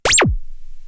不思議系効果音です。
ピチョン！・・・